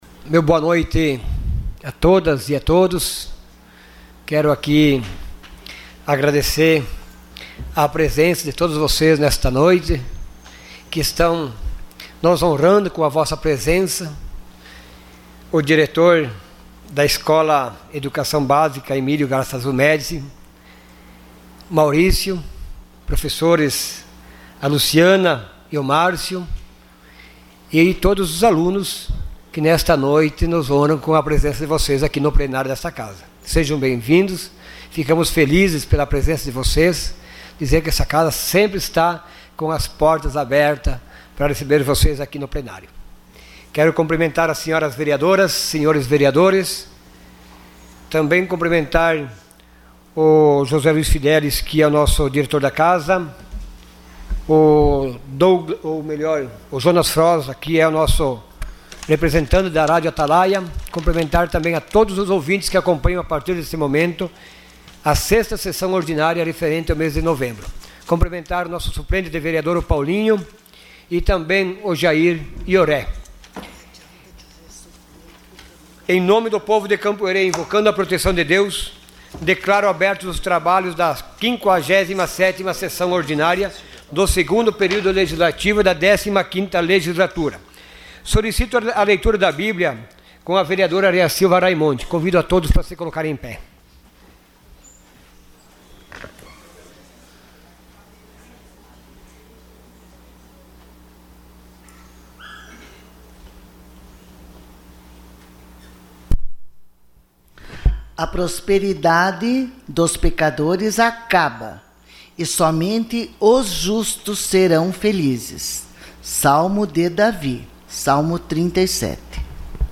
Sessão Ordinária dia 26 de novembro de 2018.